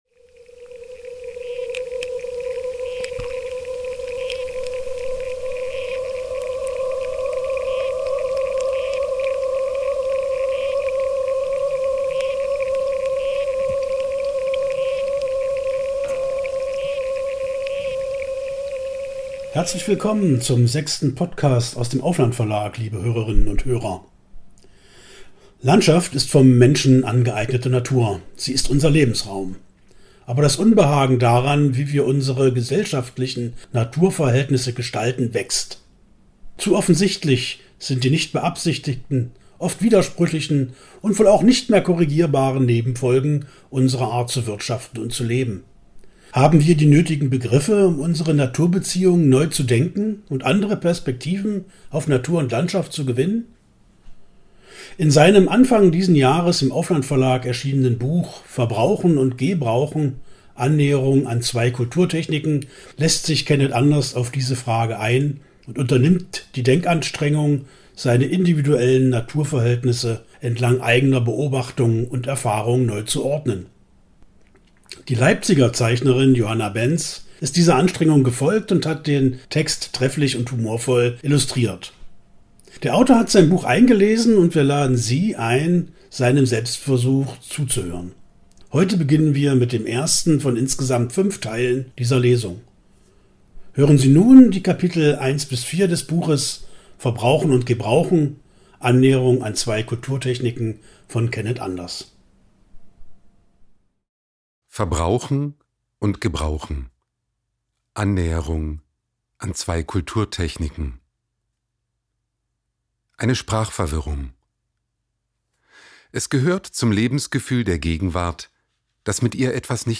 Der Autor hat sein Buch eingelesen und wir laden Sie ein, seinem Selbstversuch zuzuhören.